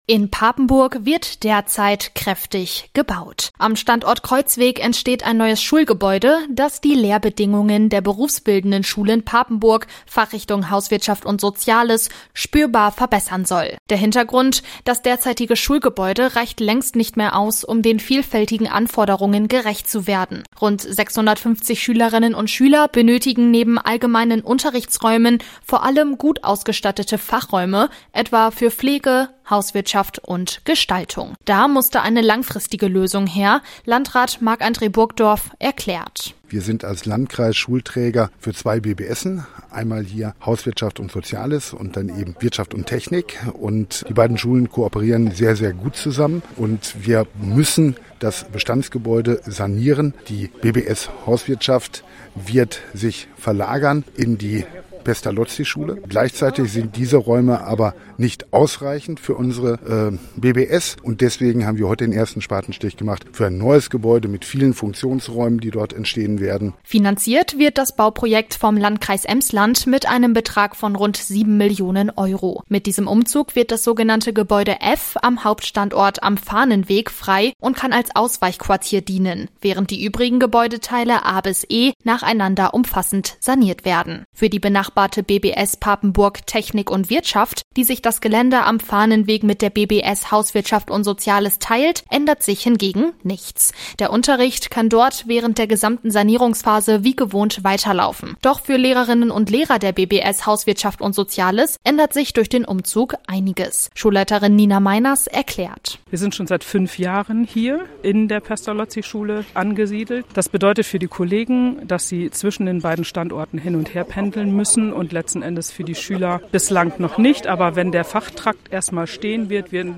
war vor Ort und berichtet: